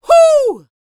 D-YELL 1902.wav